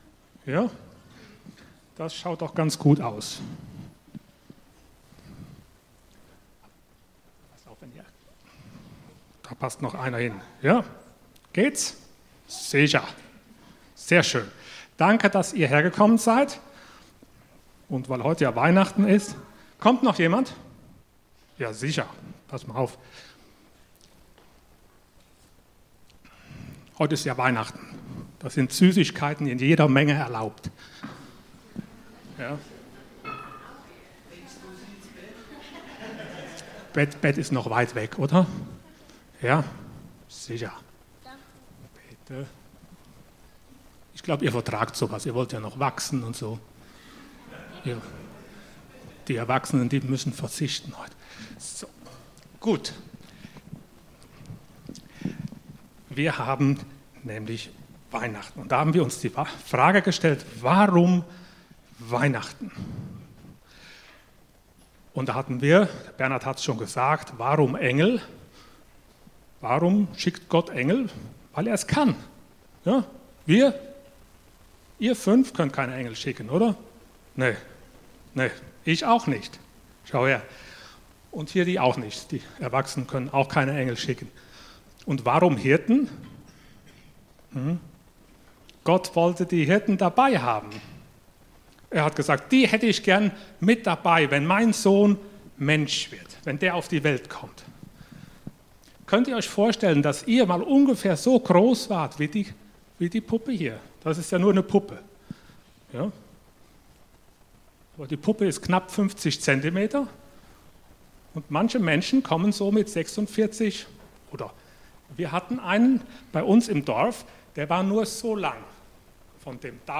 Dienstart: Weihnachten Warum Mensch? Themen: Advent , Jesu Geburt , Jesus , Mensch , Weihnachten « Warum Jude?